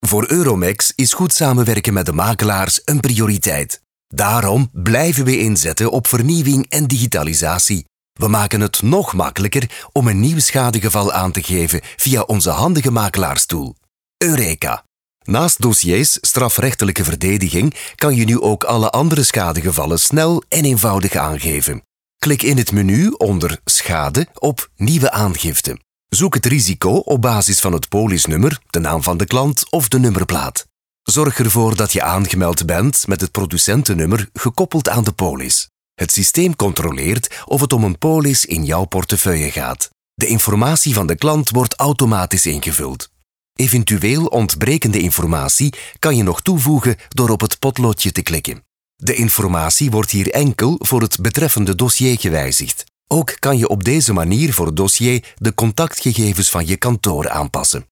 Warm, Tief, Zuverlässig, Erwachsene, Zugänglich
Erklärvideo